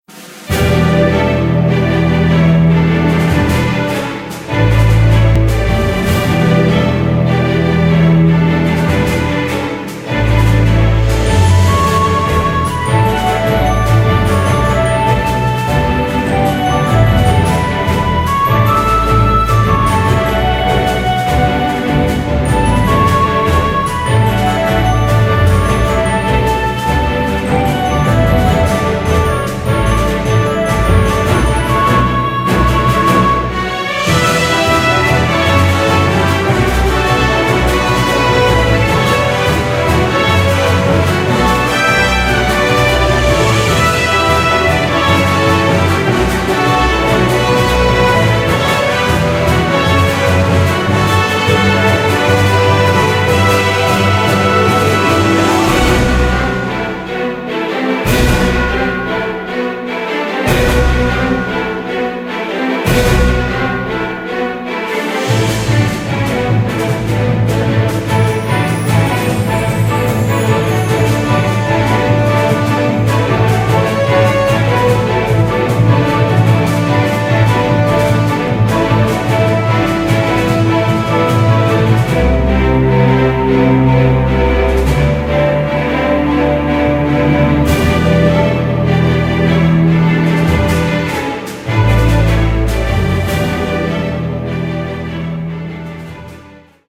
BPM150
Audio QualityPerfect (High Quality)